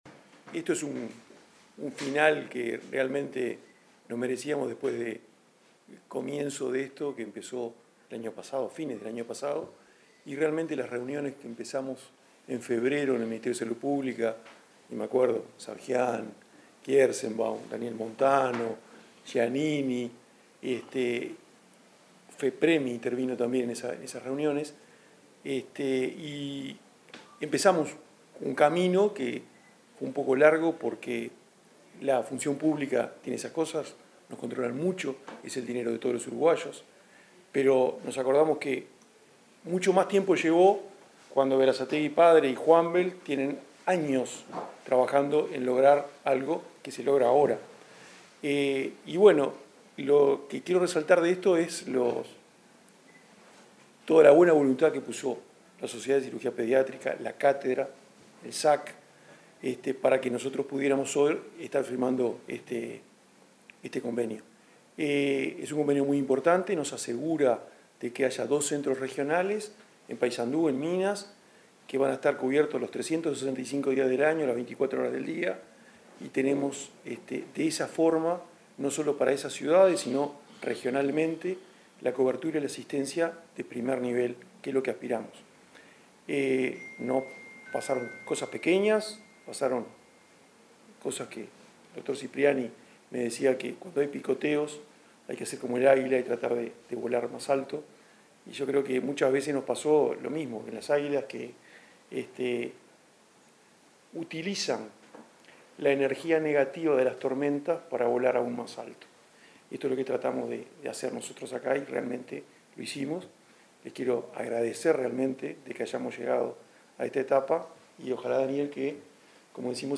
Palabras de autoridades en firma de convenio de ASSE